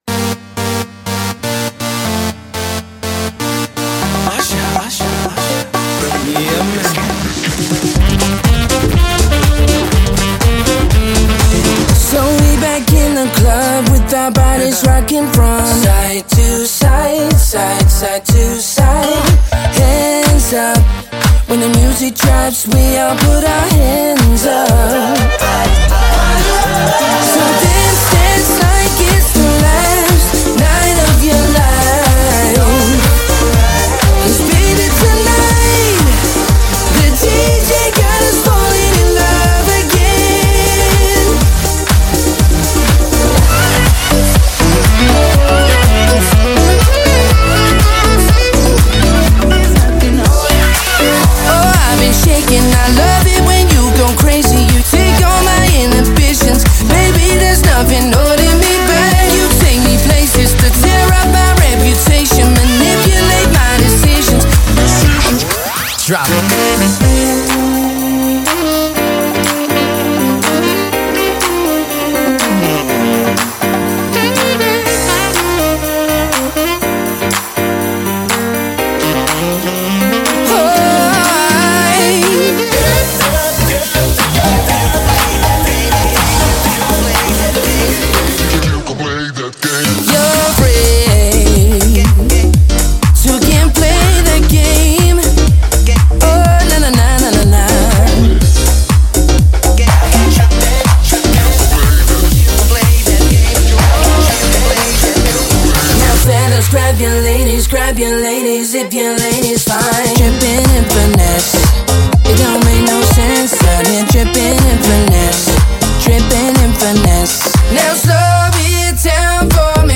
• DJ Live act with unmatched energy and charisma
• Smooth, talented and soulful male vocalist